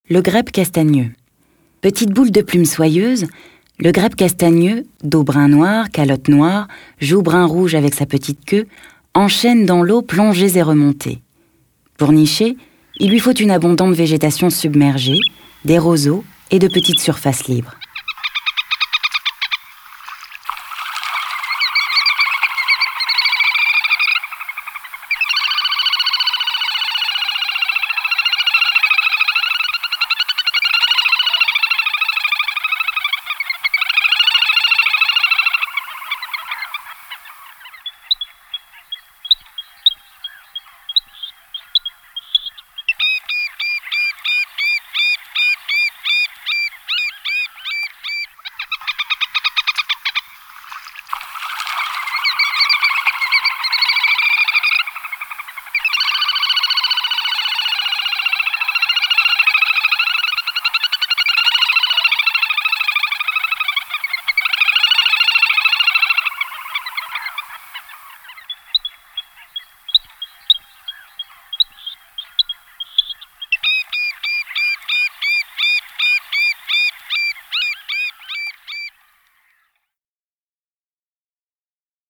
Index of / stock ancien/6/09_le_carnaval/sons oiseaux
grebe.mp3